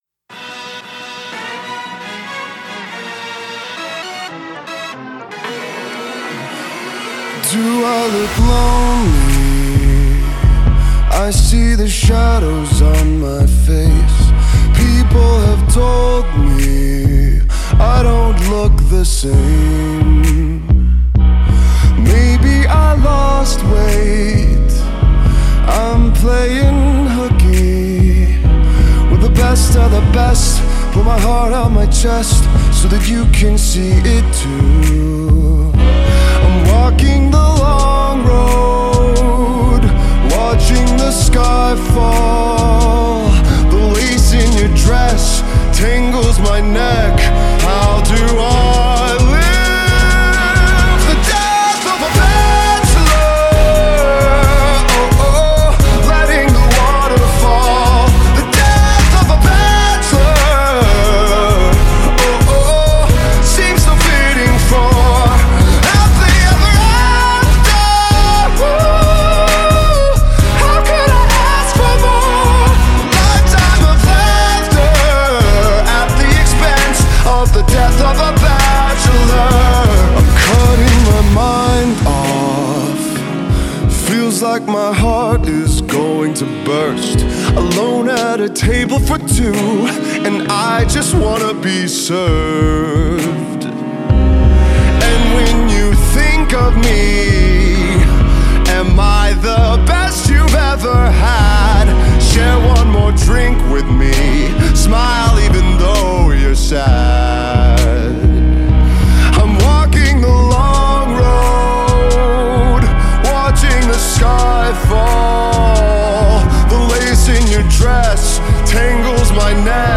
slow down